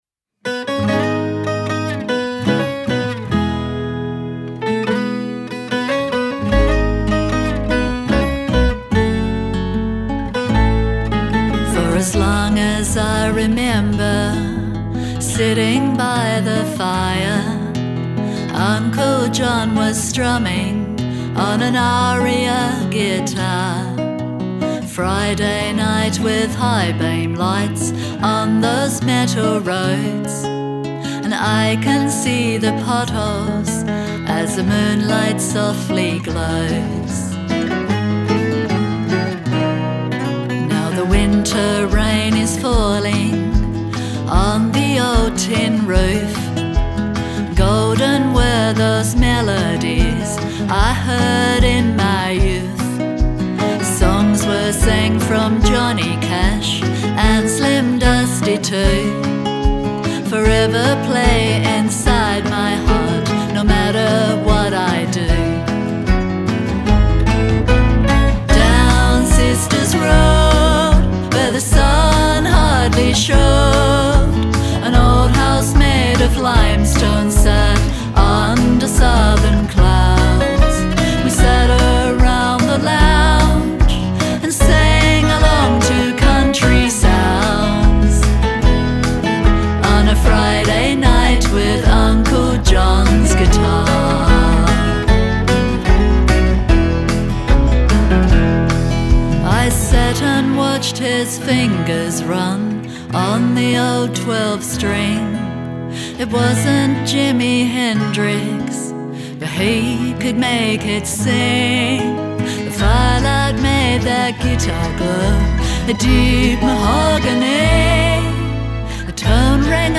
Single Release